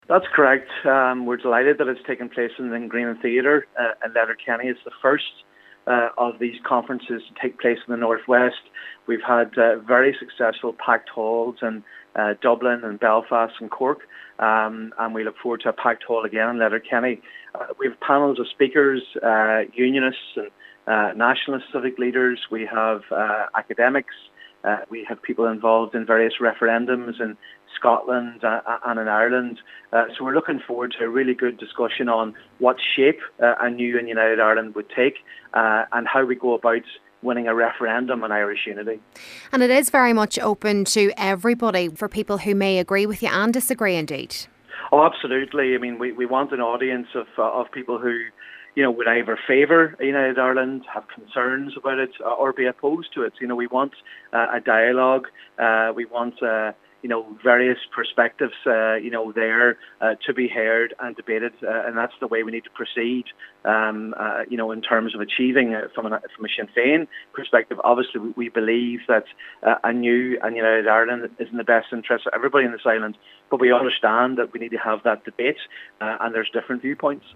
Donegal Senator Padraig Mac Lochlainn is encouraging everyone to attend.